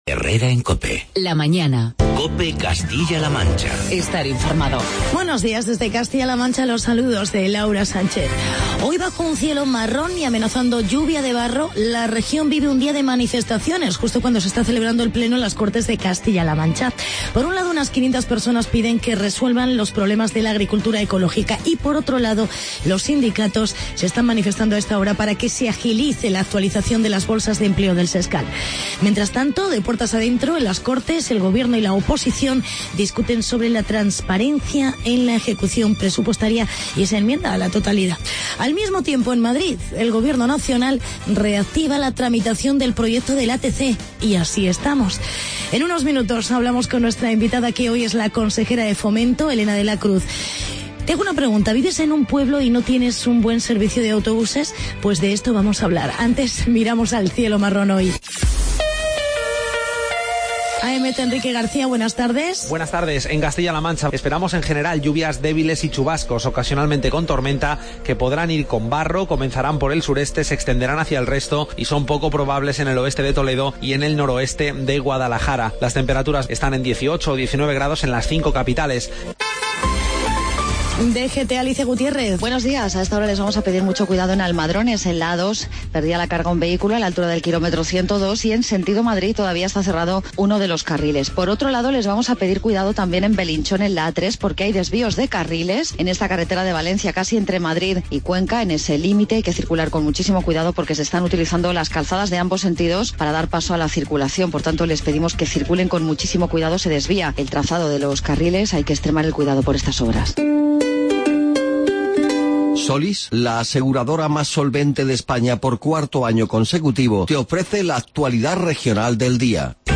Actualidad y entrevista con Elena de la Cruz, Consejera de Fomento de CLM.